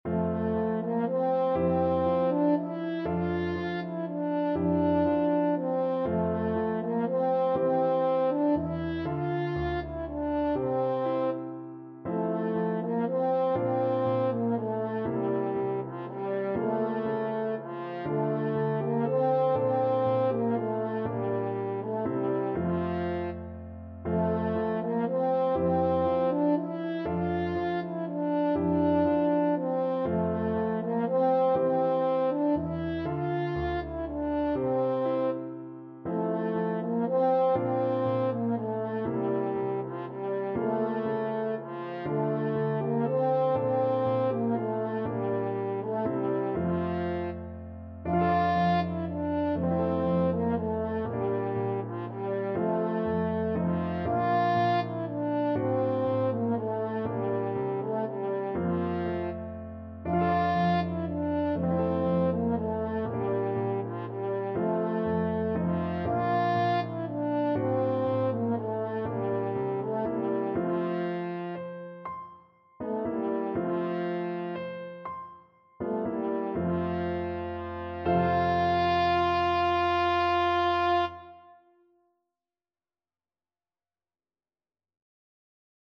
French Horn
El Noi de la Mare (The Child of the Mother) is a traditional Catalan Christmas song.
F major (Sounding Pitch) C major (French Horn in F) (View more F major Music for French Horn )
Lentissimo .= 40
6/8 (View more 6/8 Music)
F4-F5